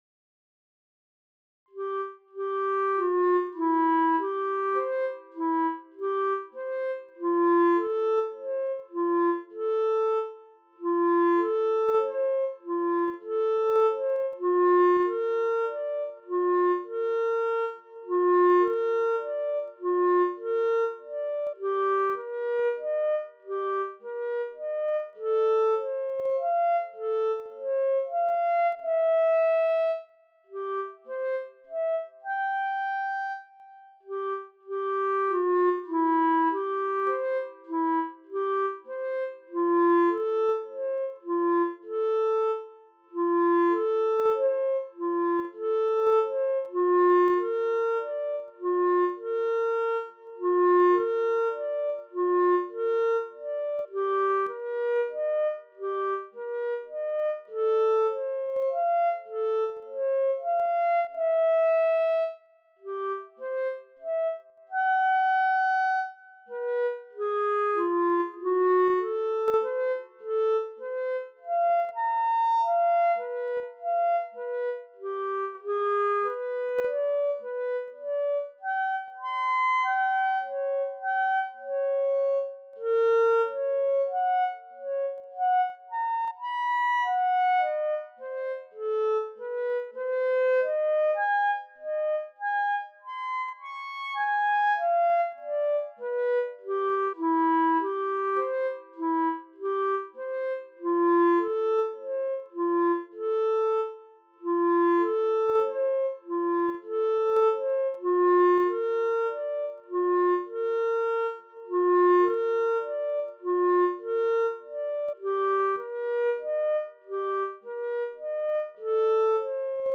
komplettes Stück in langsamem Übungs-Tempo